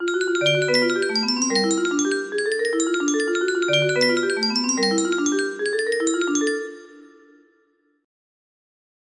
Basic1 music box melody